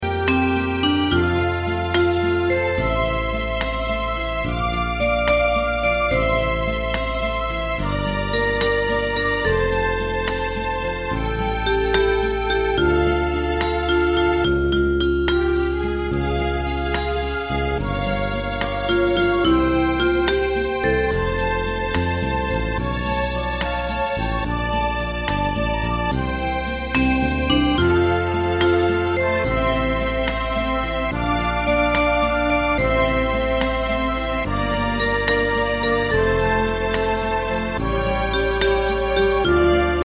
大正琴の「楽譜、練習用の音」データのセットをダウンロードで『すぐに』お届け！様々なジャンルの楽曲を取り揃えております。
カテゴリー: ユニゾン（一斉奏） .
ポピュラー